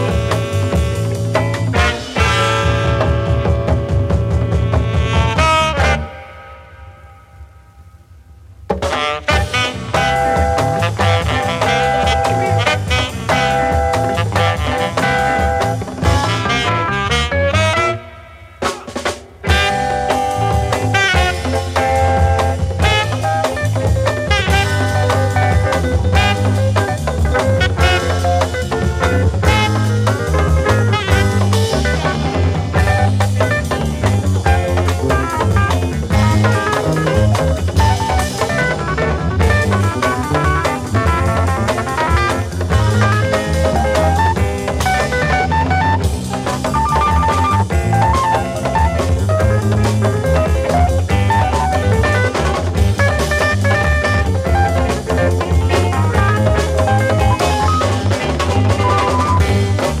The vocalist, saxophonist, composer and arranger